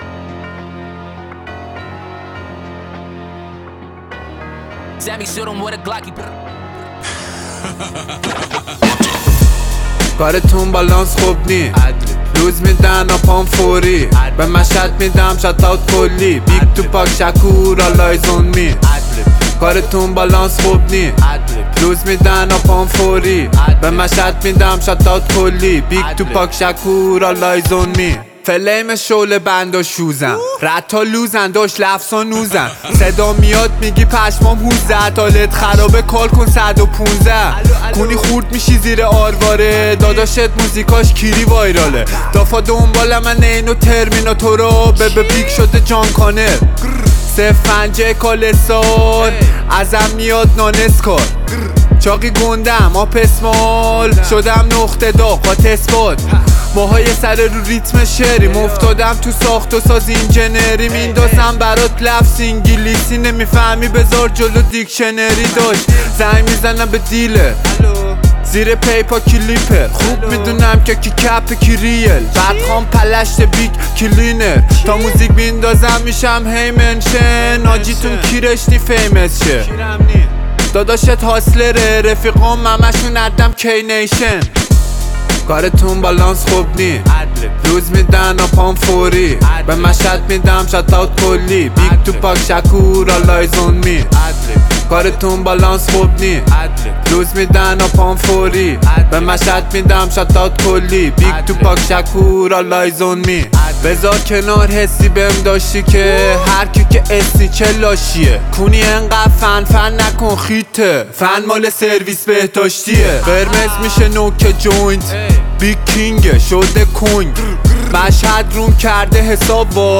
رپ
تک آهنگ